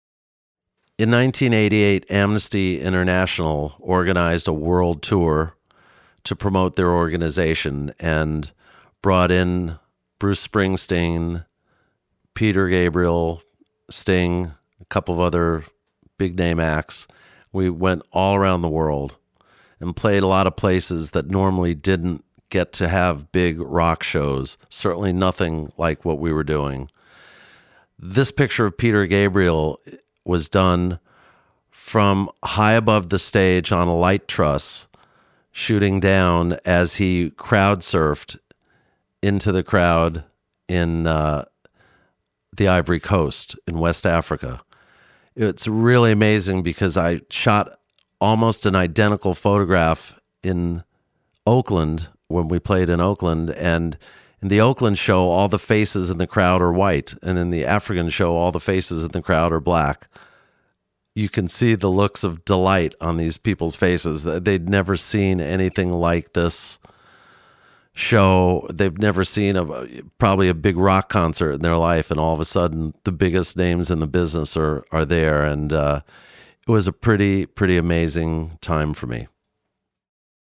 The Story behind the Picture – Original Kommentar von Neal Preston (engl.)